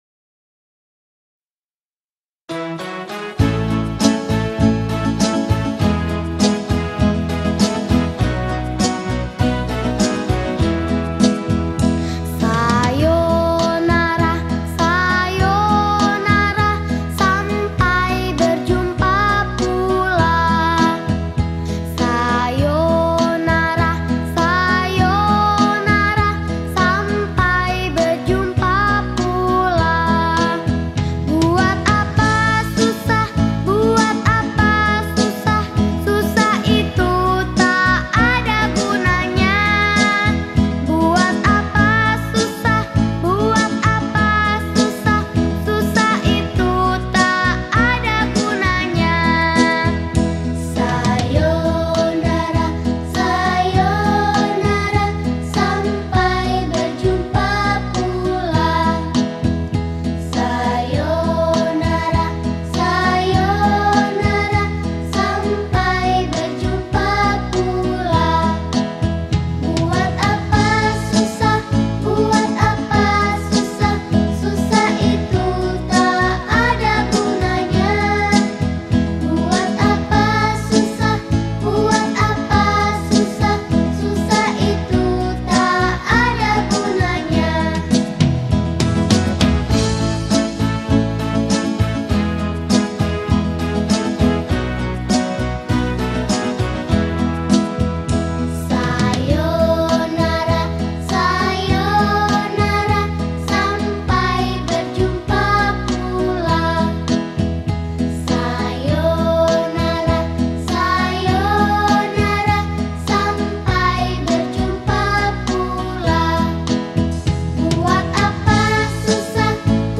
Rearranged in 2 parts harmony By
Children Song